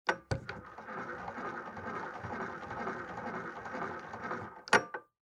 0036_Kurbelinduktor.mp3